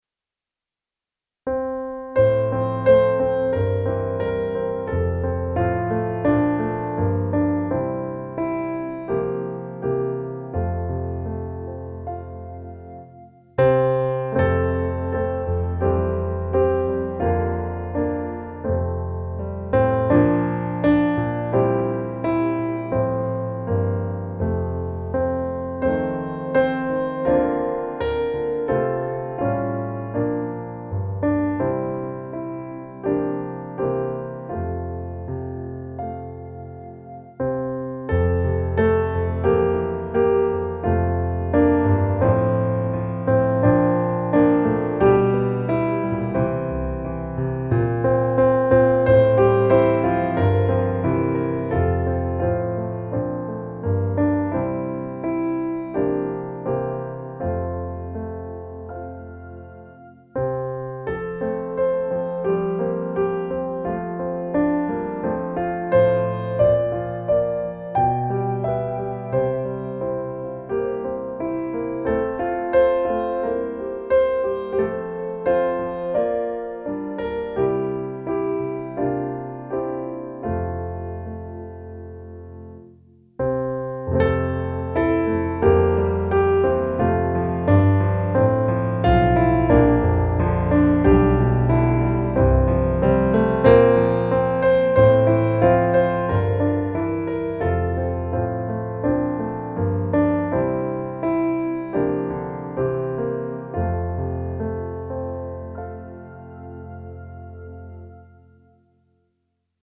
Nu tändas tusen juleljus - musikbakgrund
Musikbakgrund Psalm